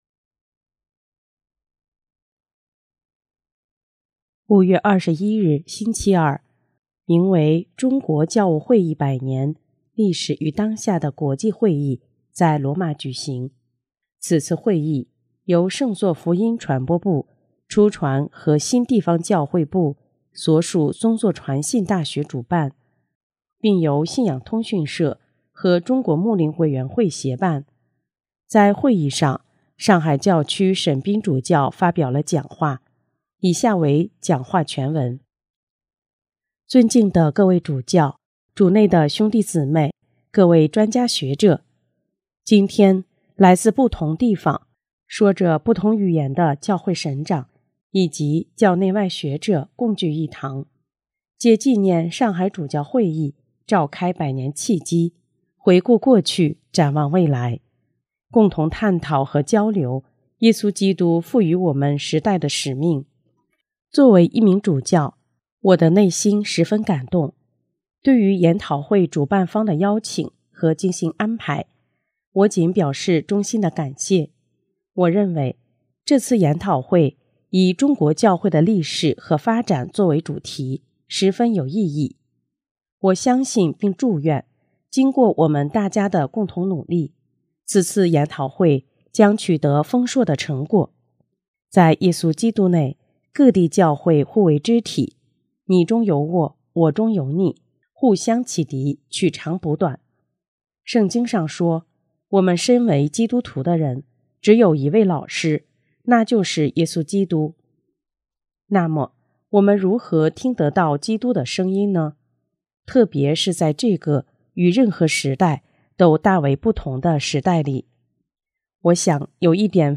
5月21日星期二，名为“中国教务会议百年：历史与当下”的国际会议在罗马举行。